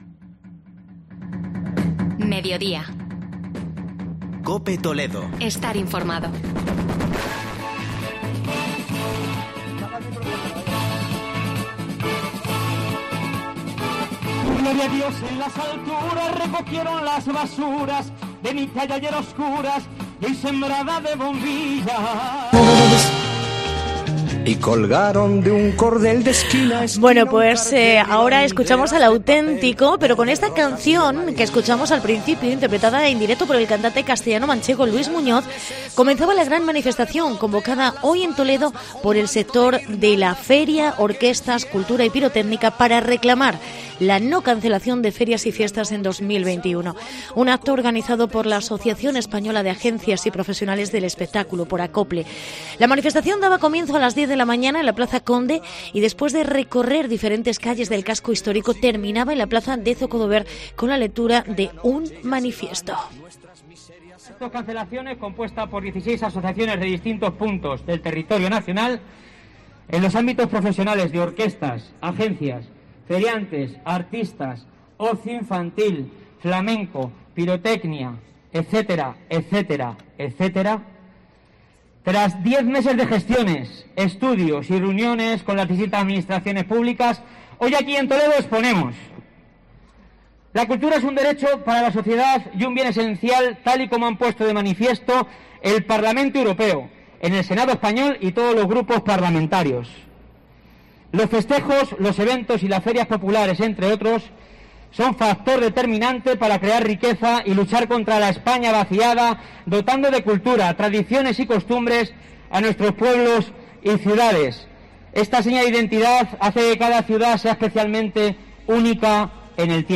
Reportaje manifestación STOP CANCELACIONES